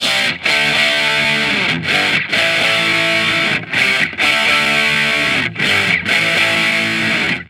Guitar Licks 130BPM (5).wav